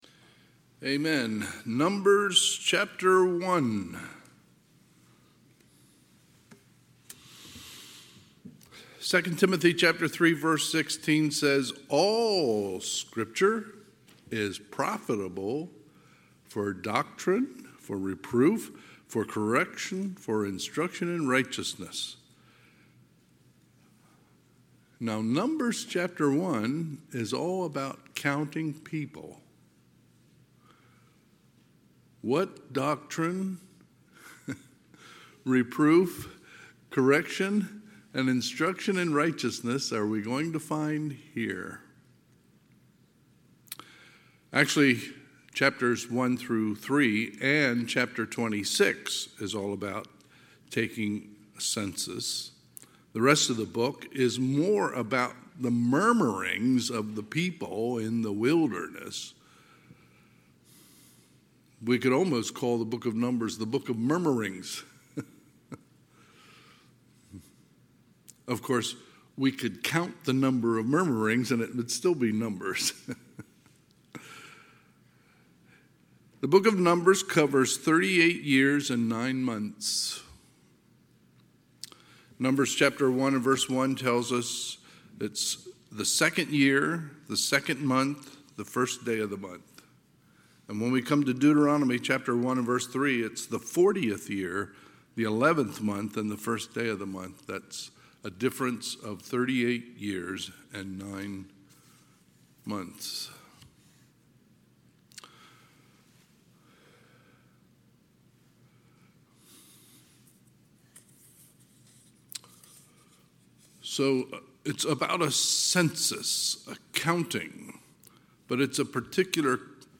Sunday, November 10, 2024 – Sunday PM